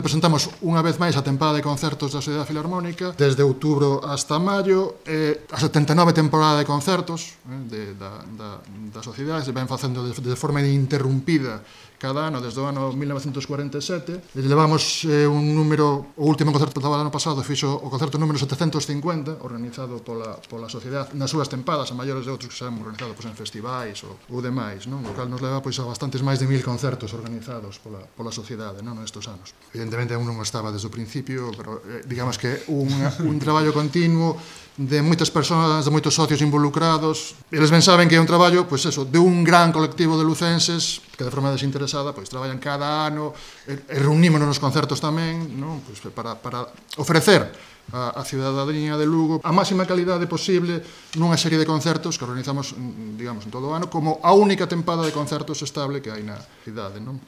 • El vicepresidente de la Diputación de Lugo, Efrén Castro, sobre la nueva temporada de la Sociedad Filarmónica de Lugo |